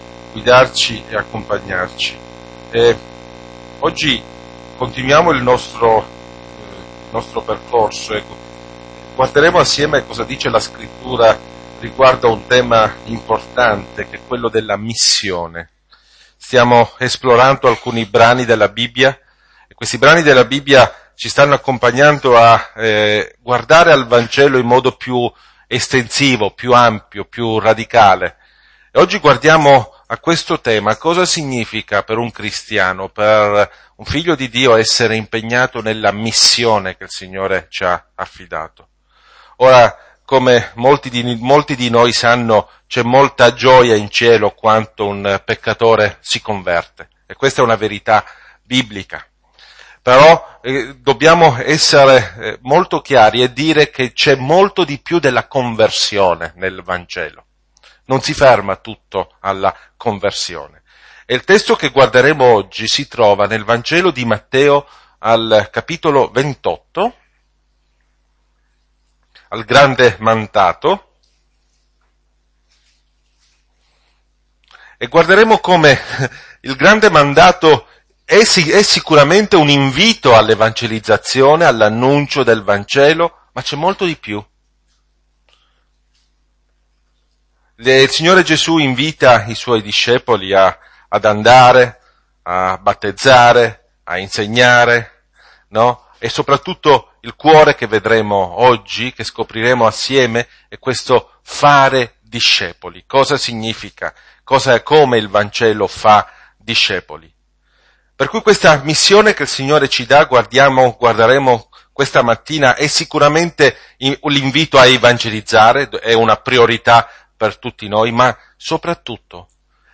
Home » Predicazioni » Il Vangelo per tutta la vita » Quale missione? Matteo 28:16-20